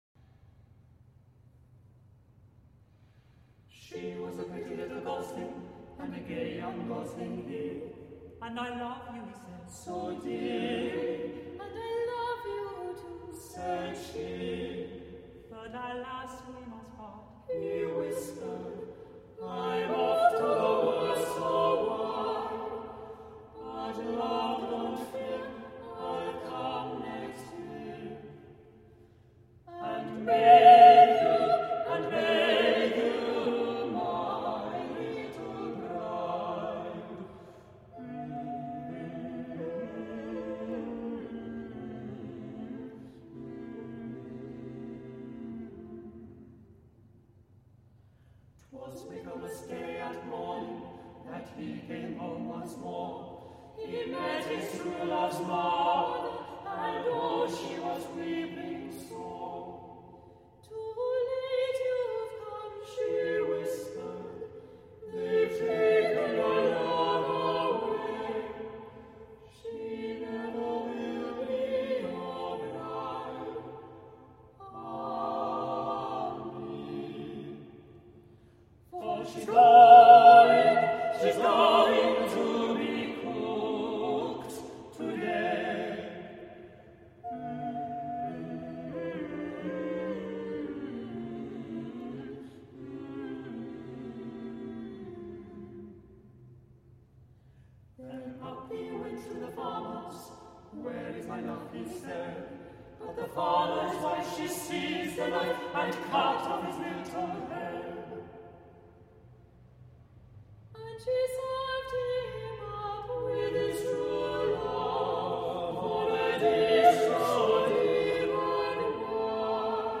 Il Suono | Vocal Ensemble | Media
Please note that the following are unedited live concert performances.